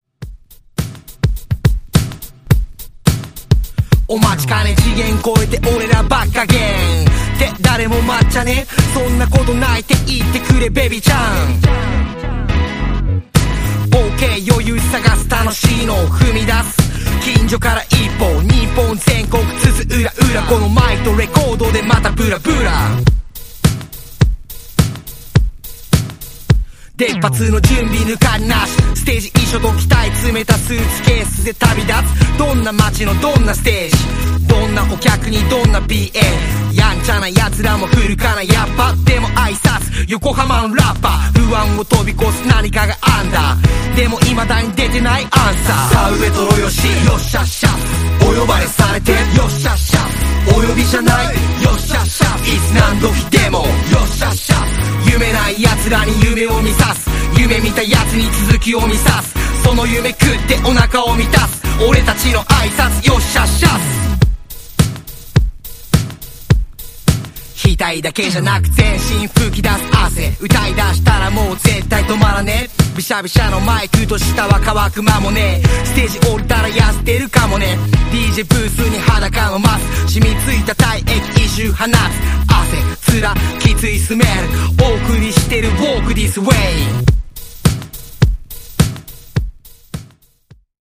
の大合唱で、オリジナル同様にアンセム化しそうなパーティー・チューンに仕上がっています。